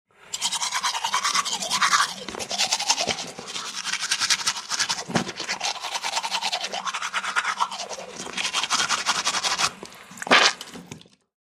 Звук быстрой чистки зубов мужчиной